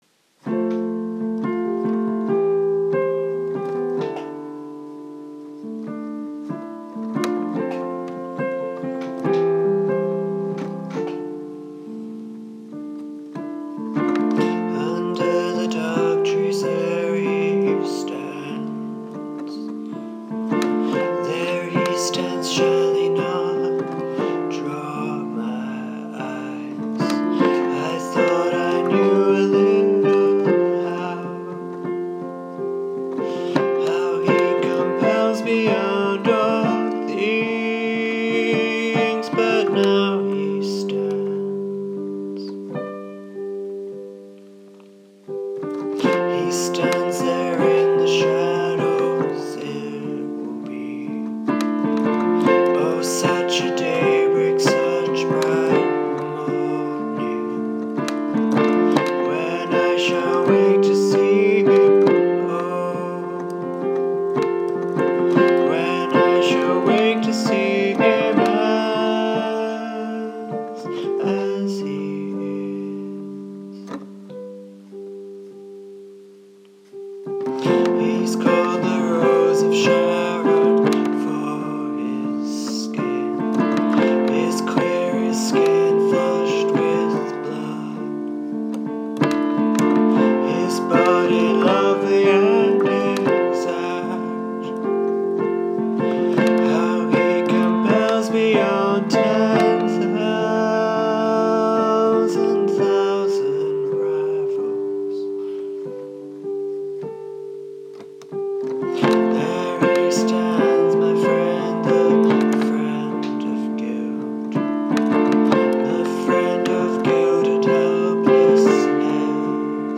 To spend a moment longer with Ann Griffiths’ poetry, I’ve set my favourite of her poems to music. Apologies for the bad quality of the recording and my singing.